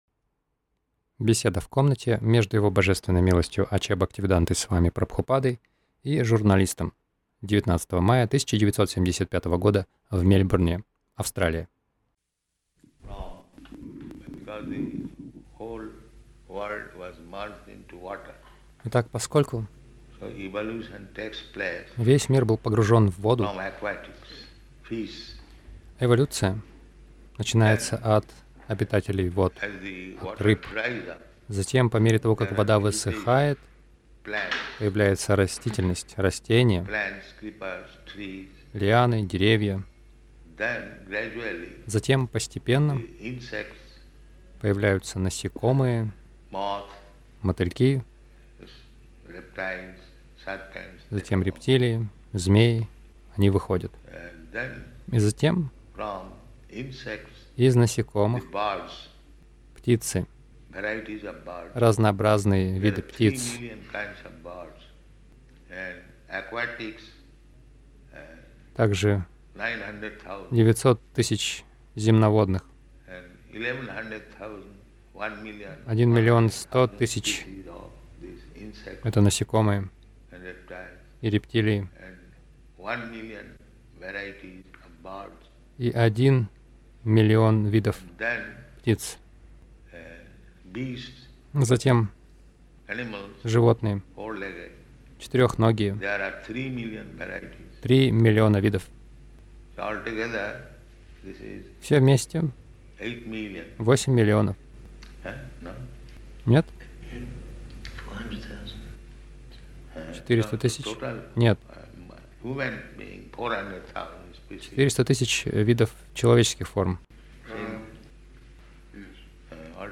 Беседа — У нас есть изначальный Отец
Милость Прабхупады Аудиолекции и книги 19.05.1975 Беседы | Мельбурн Беседа — У нас есть изначальный Отец Загрузка...